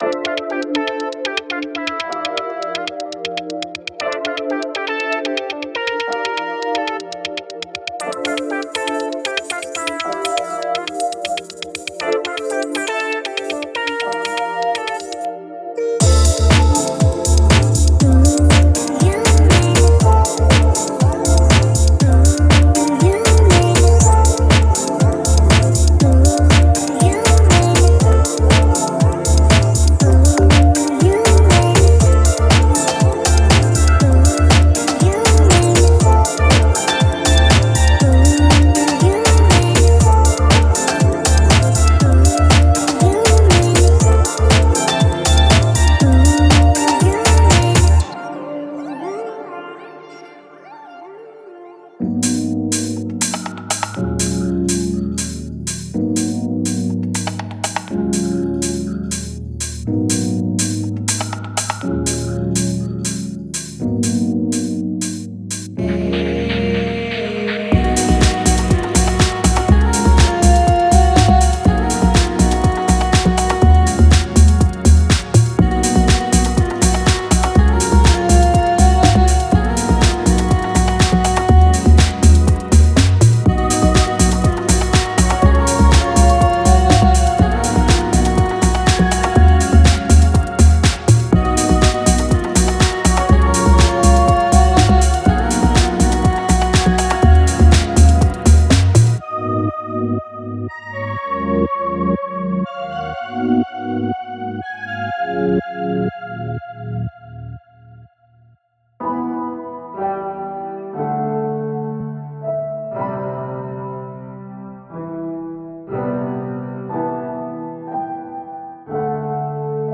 Genre:House
メロディ面では、シンセサイザー、キーボード、アコースティックギター、ピアノが揃っています。
さらに、ドリーミーな雰囲気を加えるボーカルチョップやフックもセットに含まれています。
デモサウンドはコチラ↓